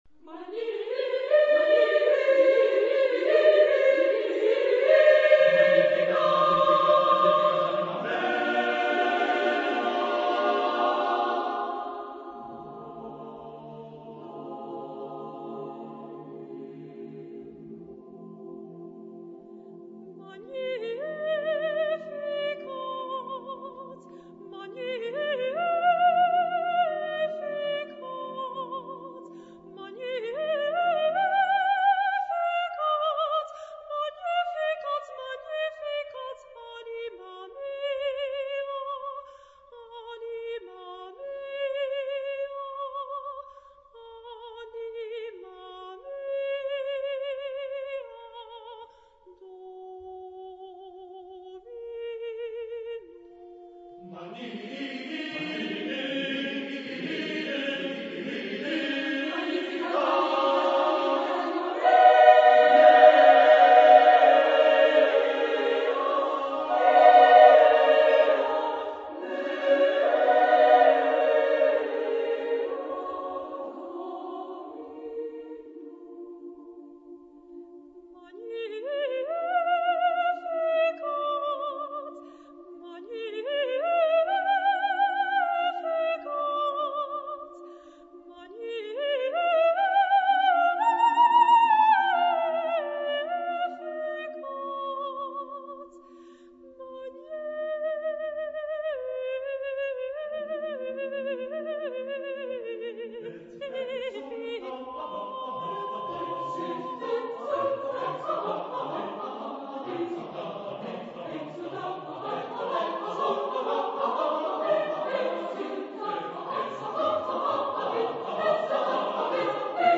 Genre-Style-Forme : Psaume ; Sacré ; contemporain
Type de choeur : SATB  (4 voix mixtes )
Solistes : Soprano (1)  (1 soliste(s))
Tonalité : do majeur